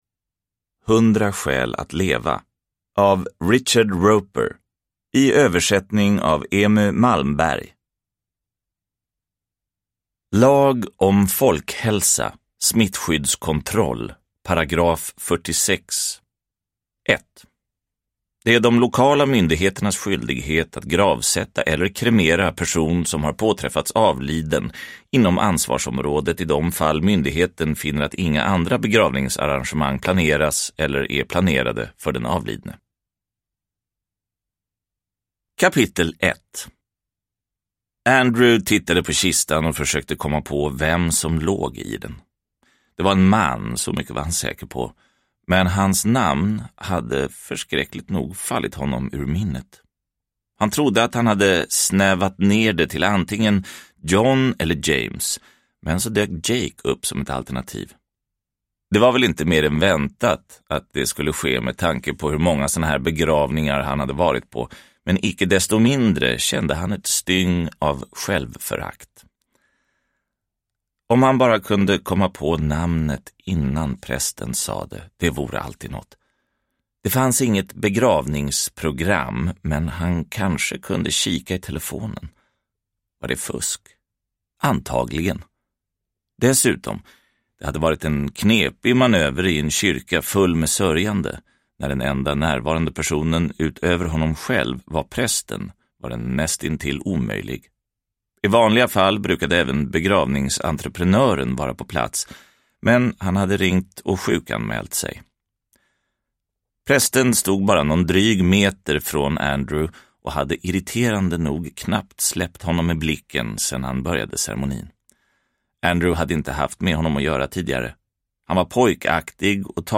Hundra skäl att leva – Ljudbok – Laddas ner